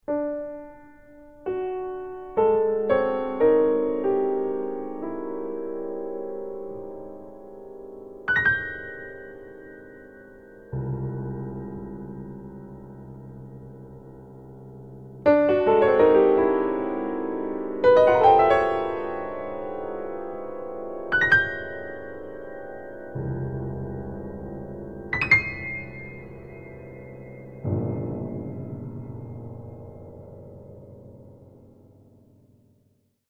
Solo Piano Arrangement